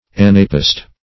Anapest \An"a*pest\, n. [L. anapaestus, Gr.